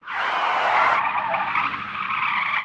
CosmicRageSounds / ogg / general / highway / oldcar / weave.ogg